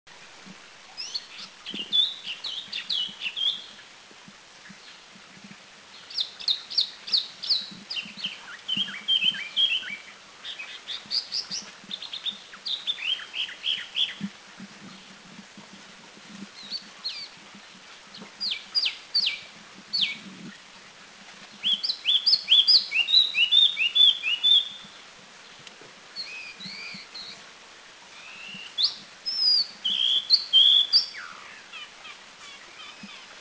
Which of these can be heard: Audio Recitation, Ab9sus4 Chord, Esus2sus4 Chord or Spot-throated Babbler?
Spot-throated Babbler